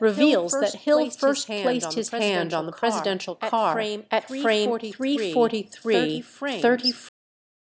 echo_0.7.wav